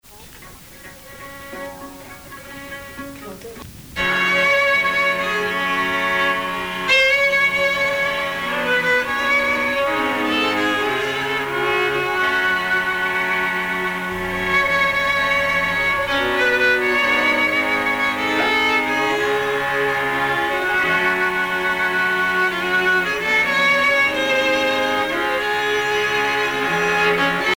orchestre
Enquête Lacito-CNRS